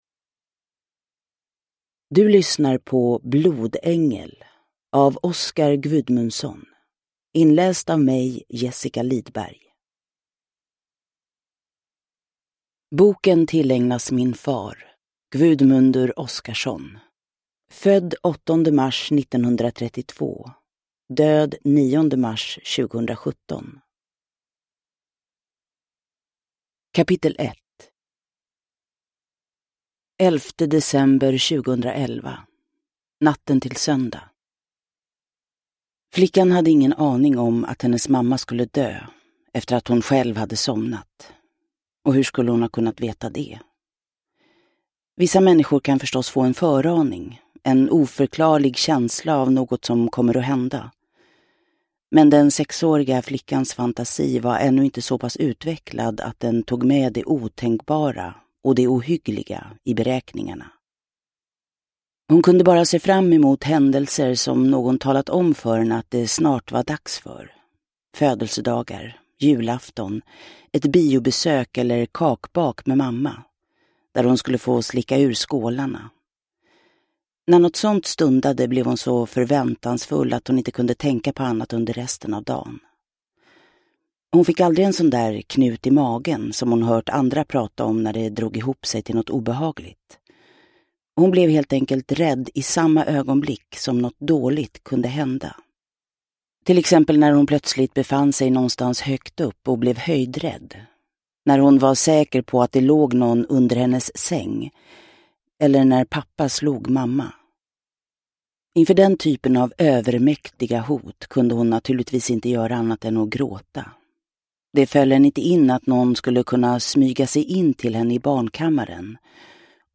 Blodängel – Ljudbok
Uppläsare: Jessica Liedberg